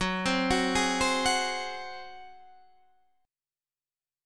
F# Suspended 2nd Flat 5th